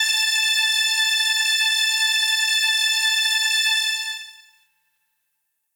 SYNTHPAD006_DISCO_125_A_SC3.wav
1 channel